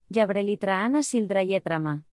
Melorin is renowned for its melodic and song-like quality, with a rhythmic cadence that flows effortlessly.
Example sentences